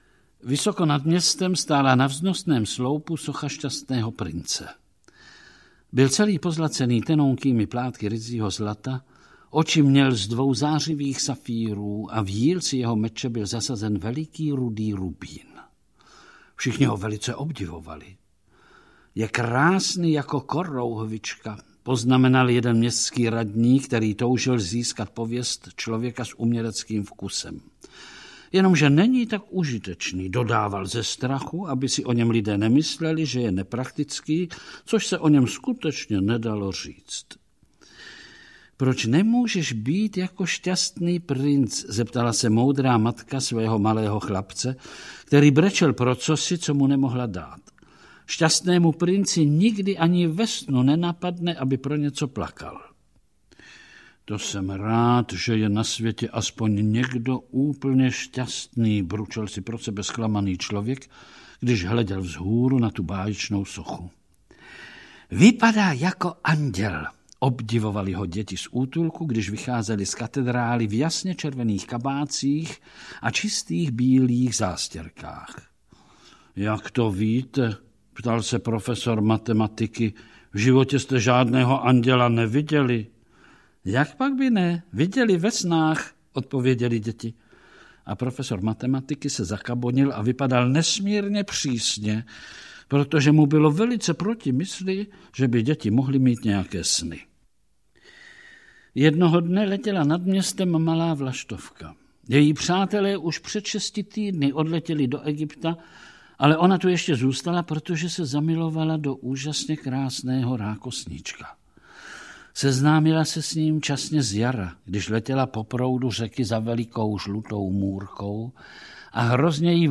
Audio kniha
• InterpretVlastimil Brodský